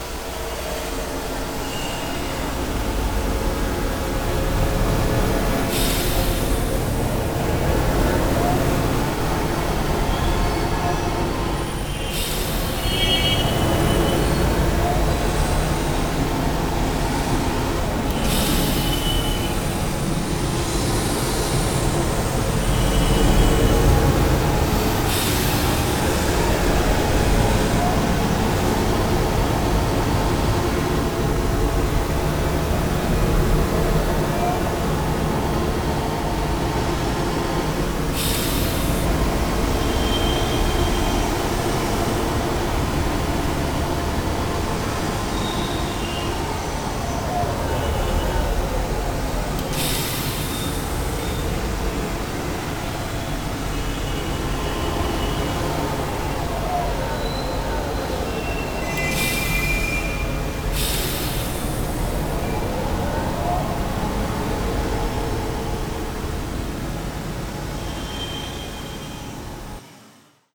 CitySound Test.wav